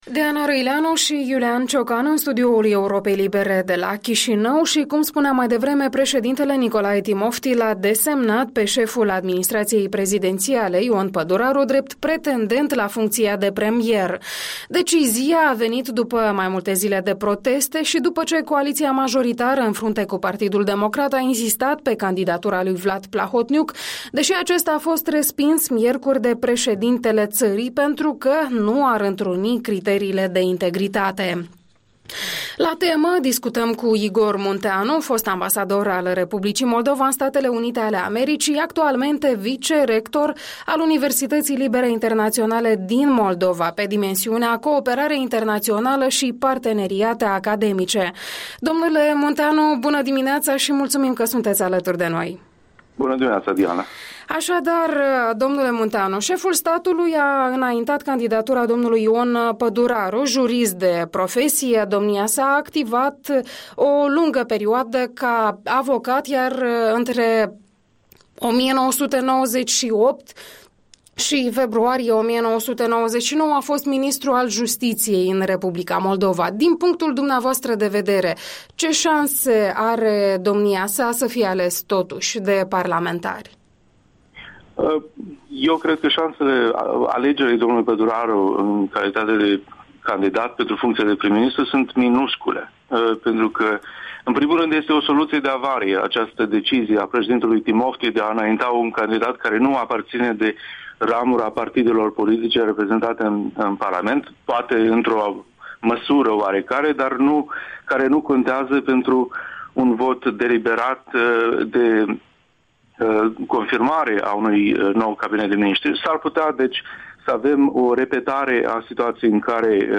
Interviul dimineții: cu prof. univ. Igor Munteanu